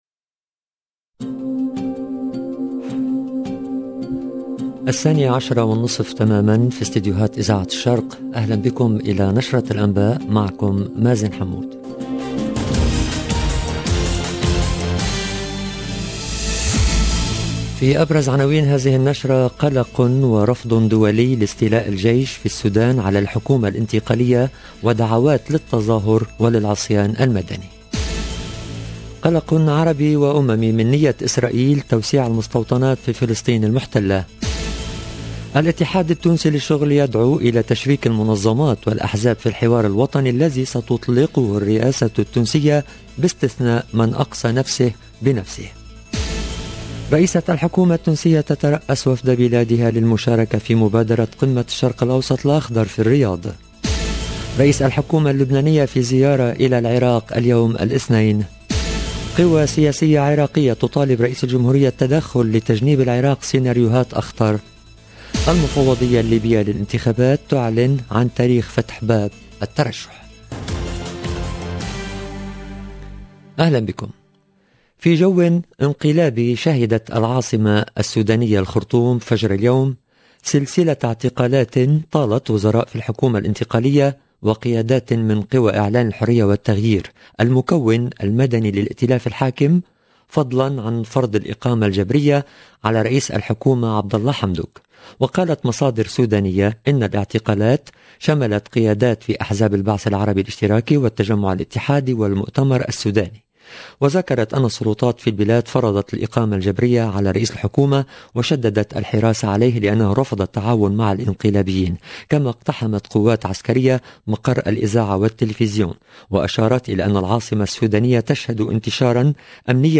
LE JOURNAL DE 12H30 EN LANGUE ARABE DU 25/10/2021